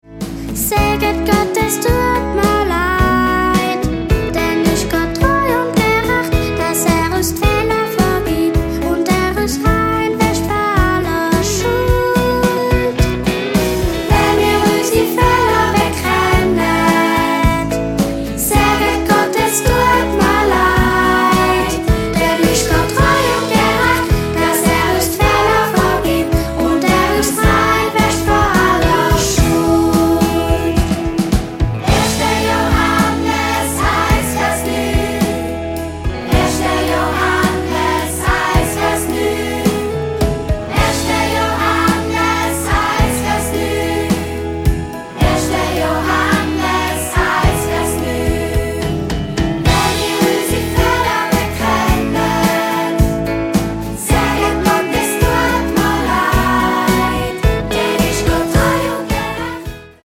20 Bibelverssongs
24 Bibelverse peppig und eingängig vertont